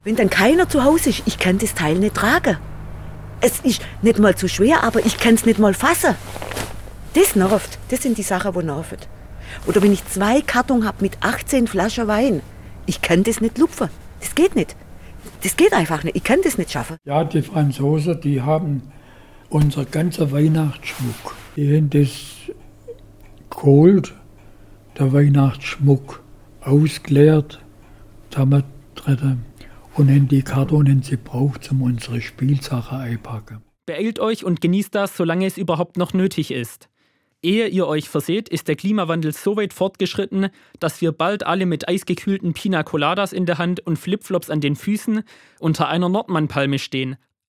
In unserer Weihnachtssendung sind wir unter anderem mit einer Zustellerin auf Tour gegangen, haben einem Zeitzeugen gefragt, wie Heiligabend in der Nachkriegszeit gefeiert wurde und haben einen Blick in die Zukunft des Weihnachtsfestes gewagt. Jetzt in den Teaser rein hören!